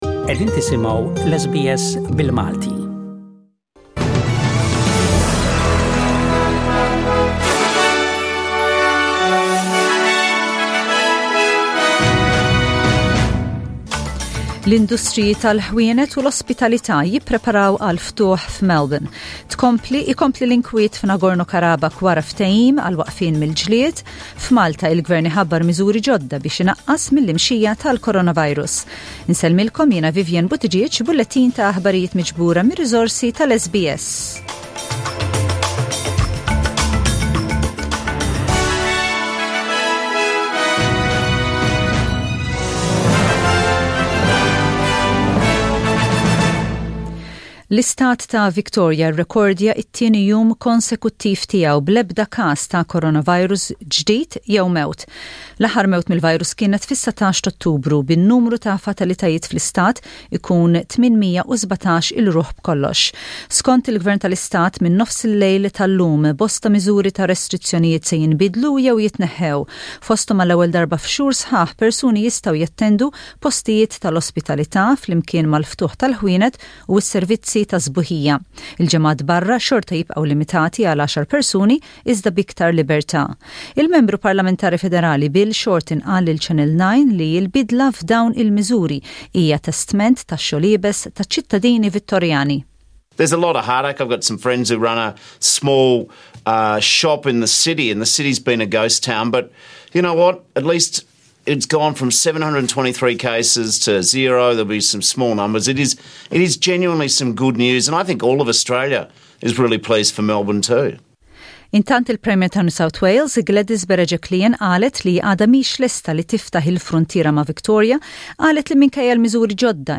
SBS Radio | Aħbarijiet bil-Malti: 27/10/20